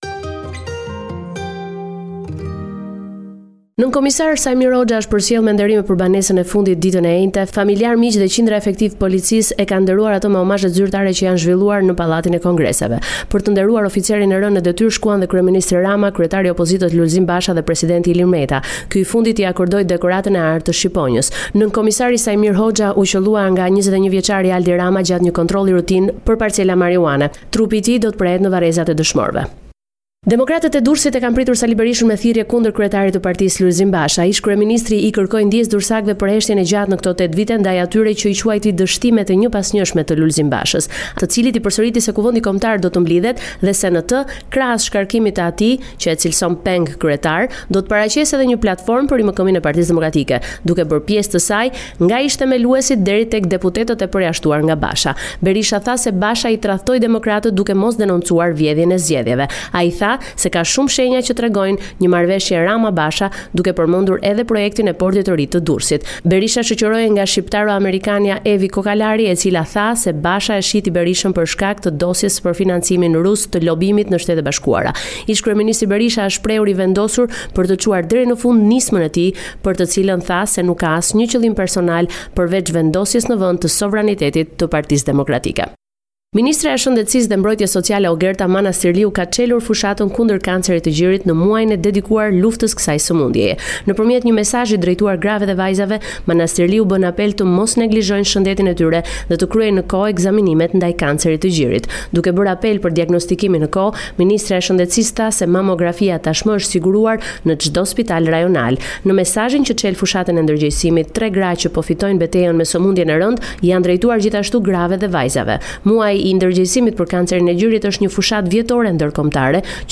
Raporti me te rejat me te fundit nga Shqiperia.